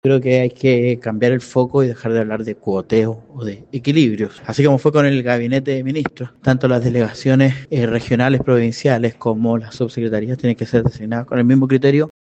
El diputado y vicepresidente de la colectividad, José Carlos Meza, pidió que no se “borre con el codo lo que se escribió con la mano”, y recalcó que el criterio que primó en la conformación del gabinete -con énfasis en perfiles técnicos- también debe aplicarse a los cargos de segunda línea.